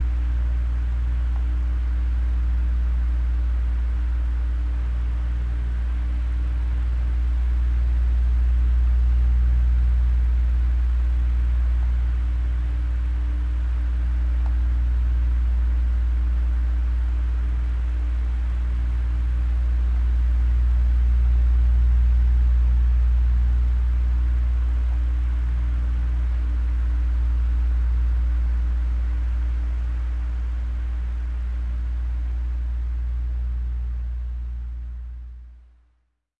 金属样品" 东1
描述：带有混响的钟声
Tag: 容器 铃盖 命中 金属 金属的 混响国度